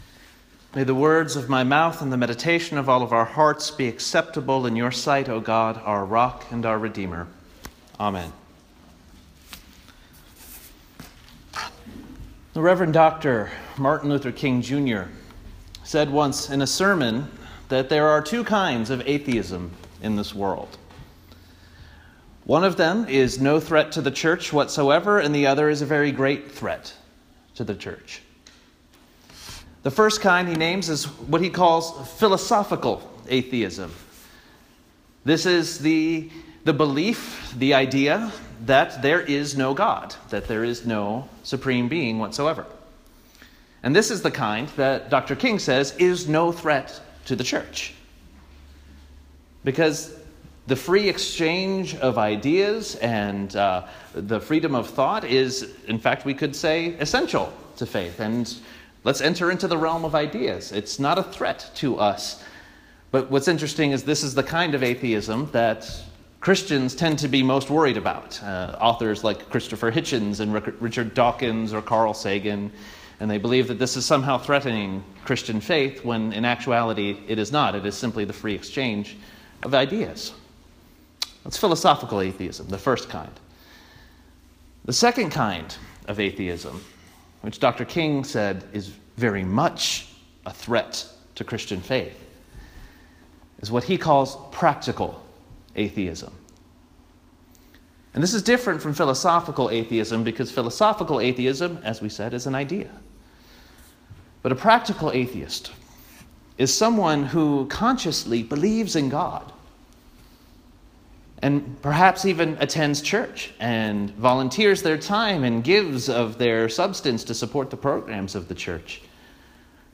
Preaching at Pennfield Presbyterian Church this week.